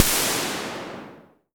Index of /90_sSampleCDs/AKAI S6000 CD-ROM - Volume 3/Crash_Cymbal1/FX_CYMBAL
EFEX CY01 SA.WAV